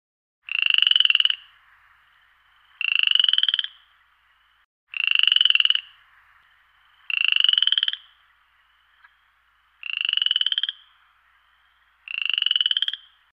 Звуки кваканья лягушки, жабы
Звук лягушки у пруда